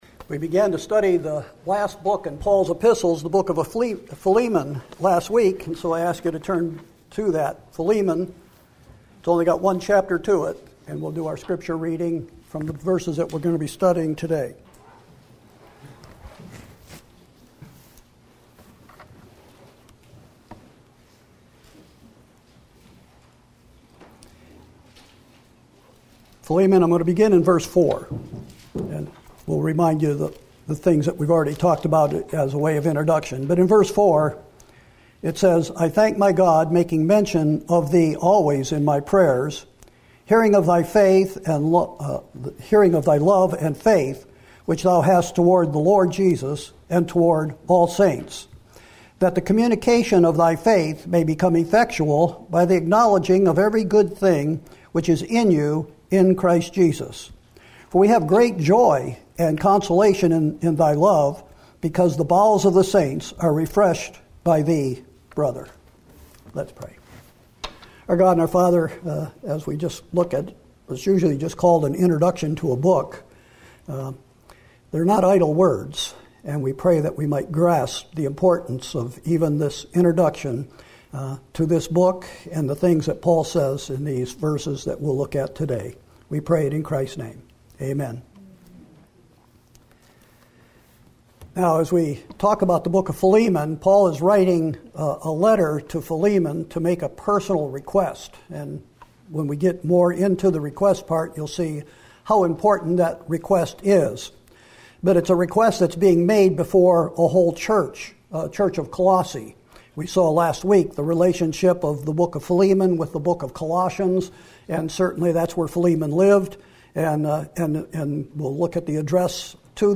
Sermons & Single Studies